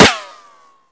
weapons